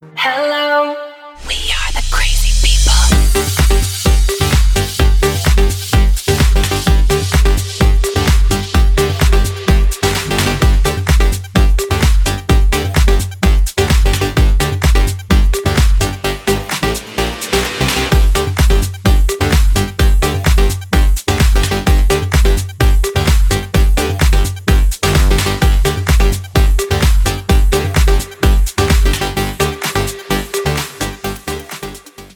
• Качество: 320, Stereo
club